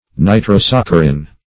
Search Result for " nitrosaccharin" : The Collaborative International Dictionary of English v.0.48: Nitrosaccharin \Ni`tro*sac"cha*rin\, n. [Nitro- + saccharin.]
nitrosaccharin.mp3